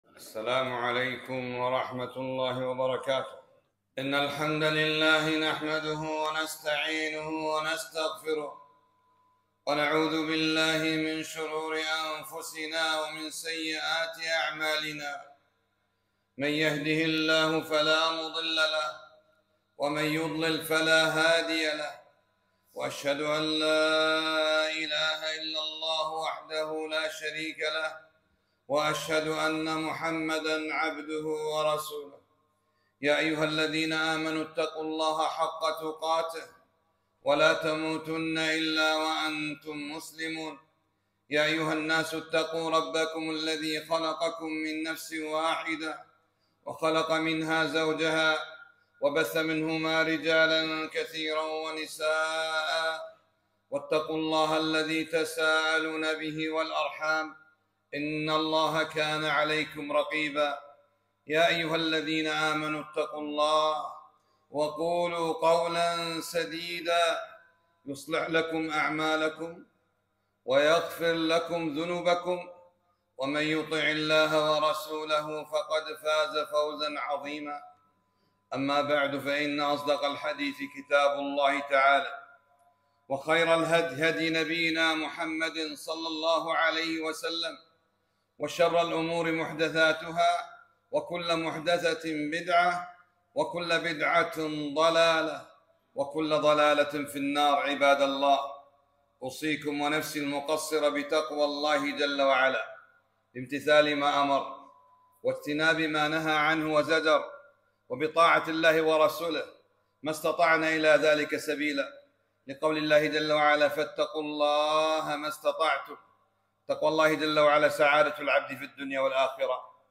خطبة - إنما الحِلمُ بالتَّحلُّم